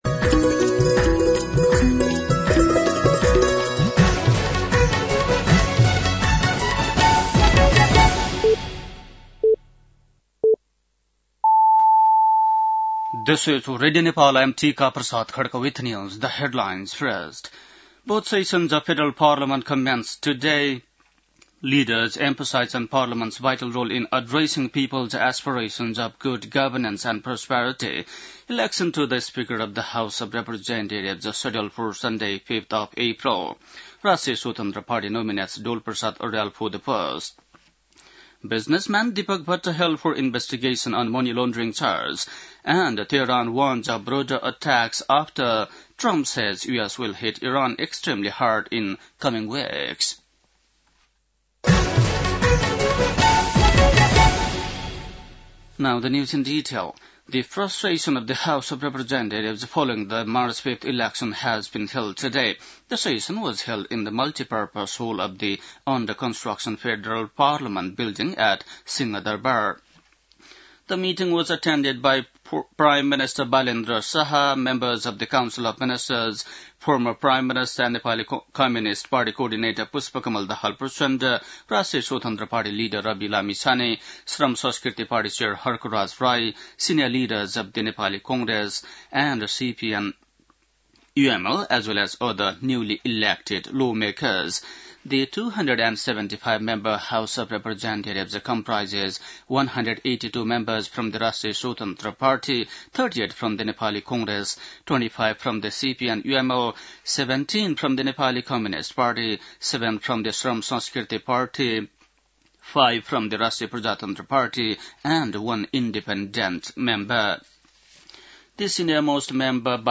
बेलुकी ८ बजेको अङ्ग्रेजी समाचार : १९ चैत , २०८२
8-pm-news.mp3